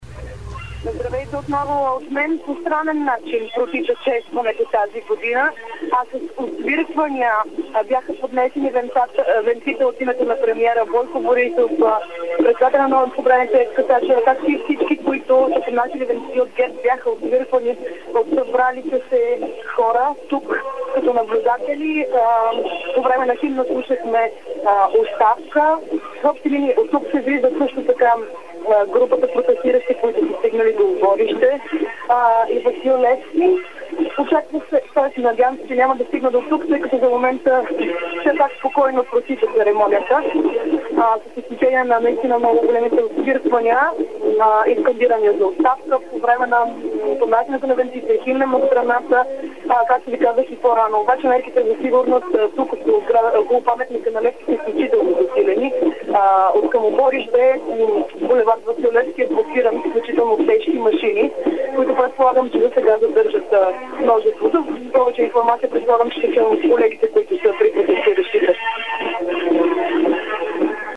Президентът и ГЕРБ освиркани край паметника на Левски